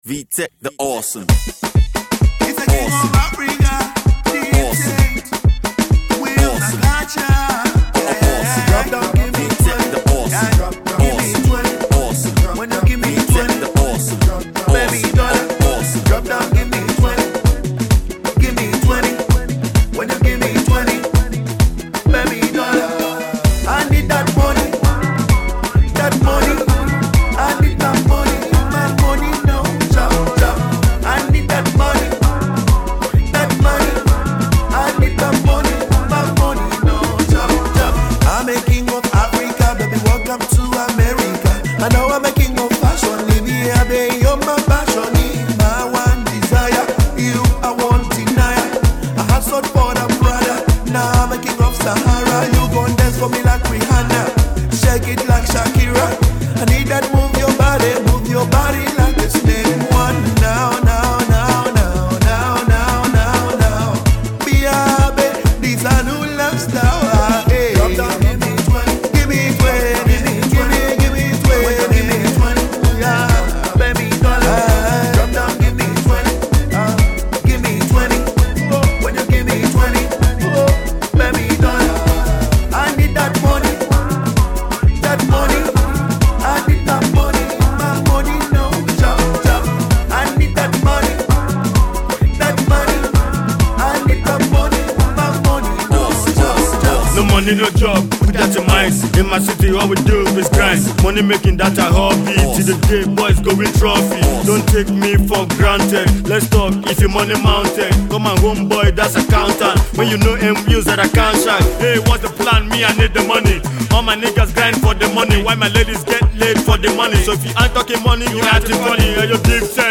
Zambian/American act decide to hit the dance Floor
heavy weight jam
lick up the mic with some hot bars